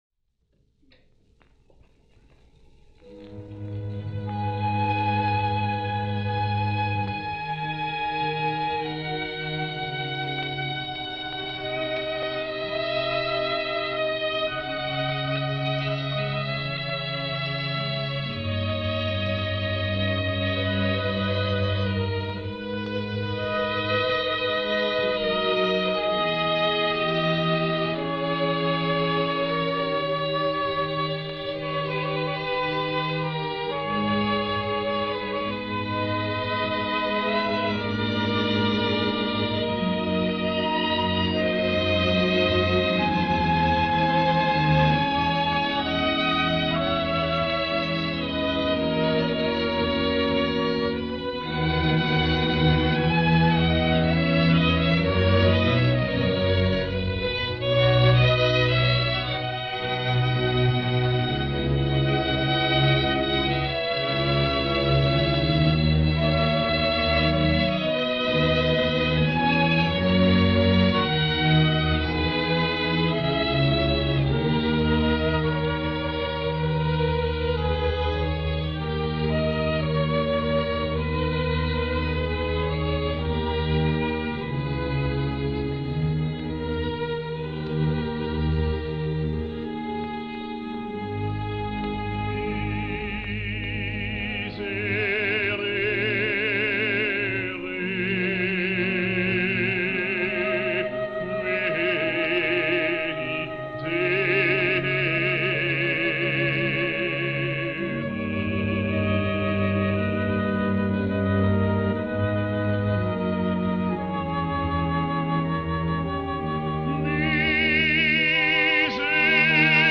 Miserere (extended excerpts) – circa 1953 – ORTF, Paris
for Chorus, soloists, organ and Orchestra
Unfortunately, this is only an extended excerpt (less than some 20 minutes) of the first 20th century performance.